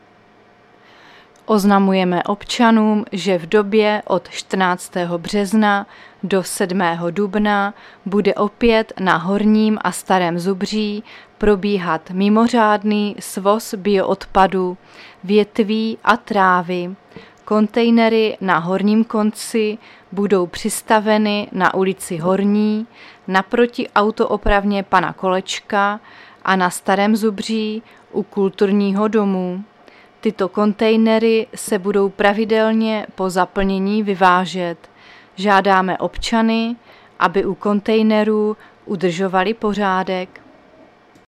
Záznam hlášení místního rozhlasu 13.3.2025
Zařazení: Rozhlas